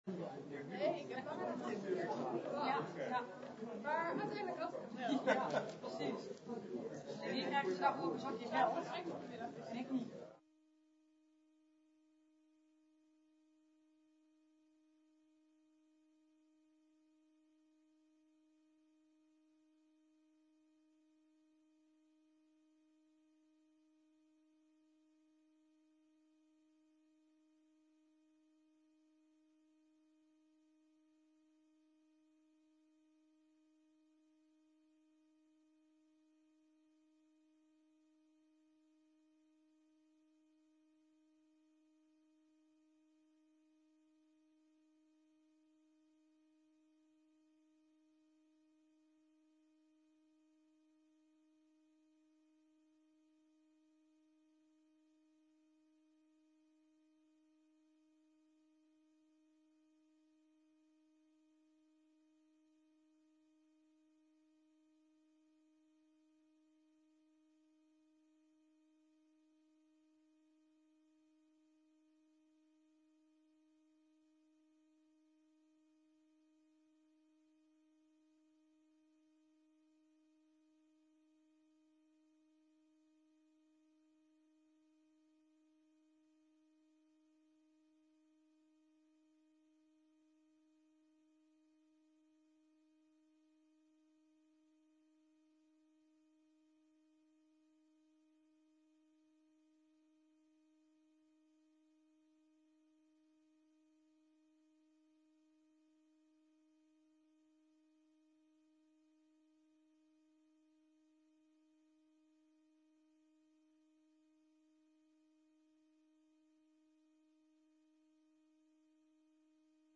De vergadering wordt gehouden in het Stadshuis met in achtneming van de 1,5 meter regel.